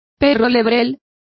Complete with pronunciation of the translation of whippet.